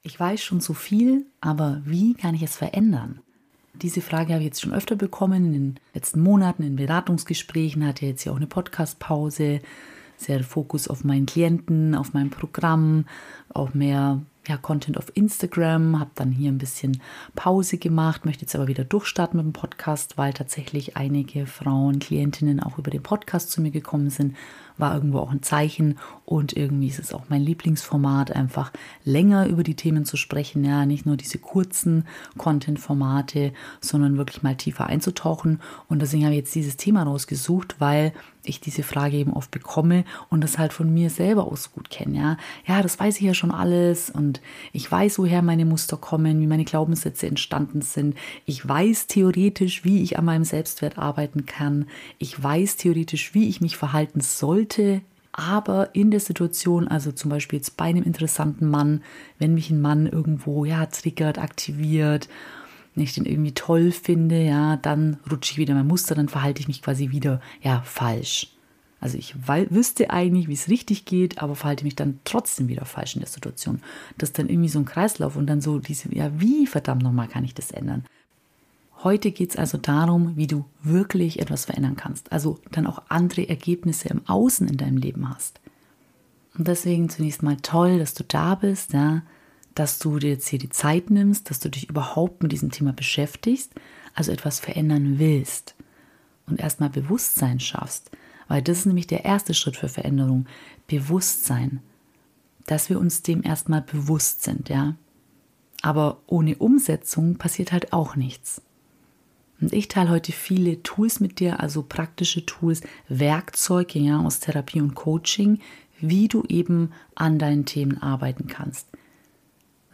10 Jahre innere Arbeit in 1 Stunde - ein transformativer Workshop für echte Veränderung